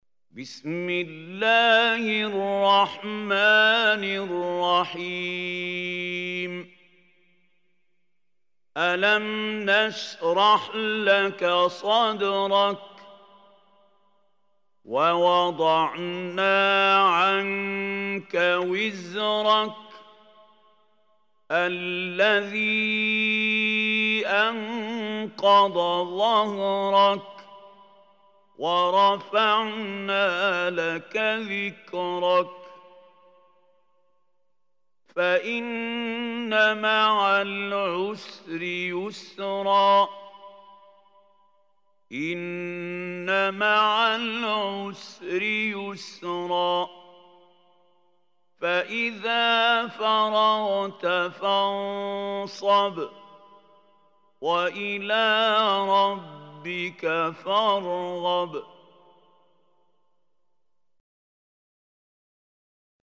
Surah Inshirah MP3 Recitation by Mahmoud Khalil
Surah Inshirah is 94 surah of Holy Quran. Listen or play online mp3 tilawat / recitation in Arabic in the beautiful voice of Sheikh Mahmoud Khalil Hussary.